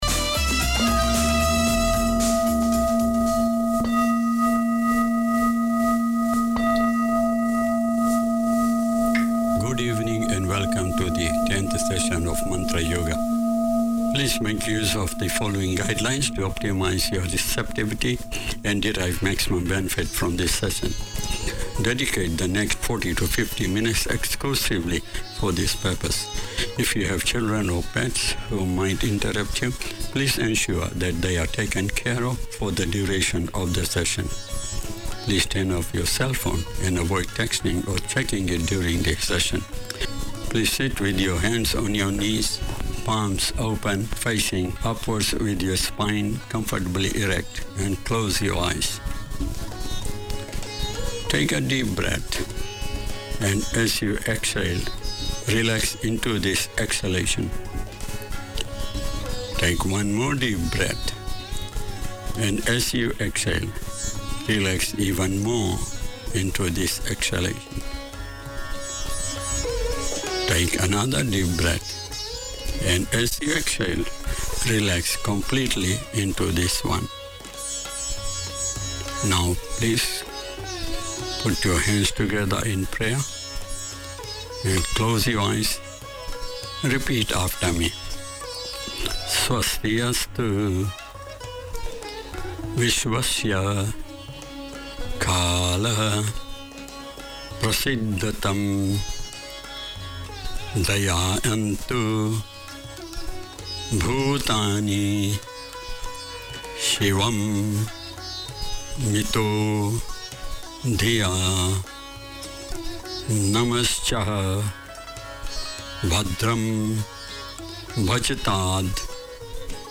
Te Ama Pasefika Health is promoting the well-being of Pacific people. Each week you’ll hear interviews with studio guests giving advice on health, education, employment and other support services that encourage wellness and foster healthy, happy lives for Pasefika people in New Zealand.